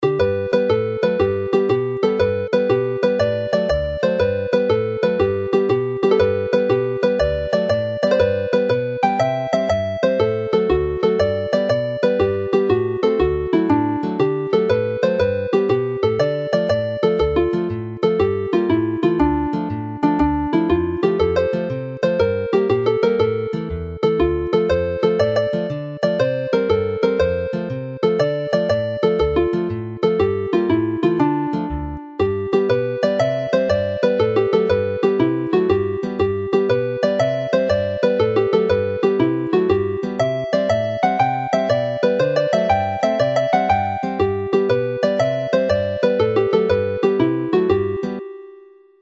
This set of jigs